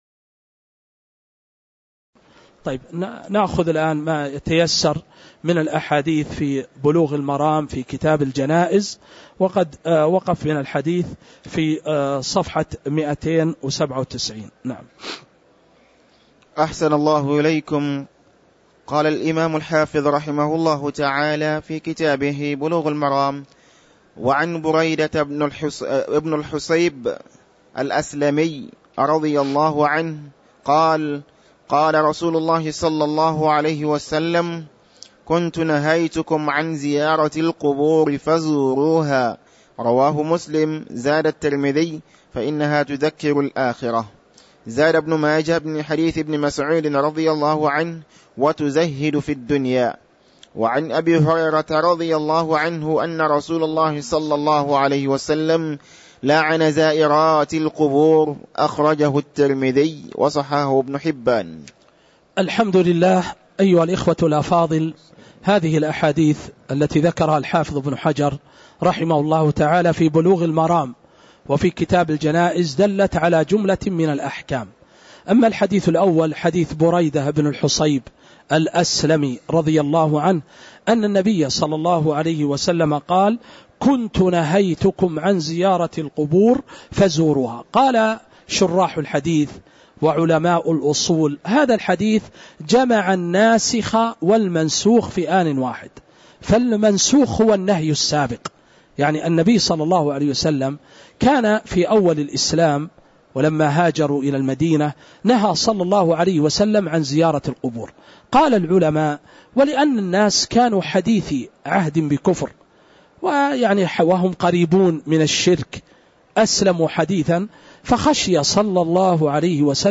تاريخ النشر ٢١ شعبان ١٤٤٥ هـ المكان: المسجد النبوي الشيخ